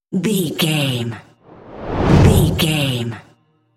Dramatic whoosh to hit trailer
Sound Effects
Fast paced
In-crescendo
Atonal
intense
tension
woosh to hit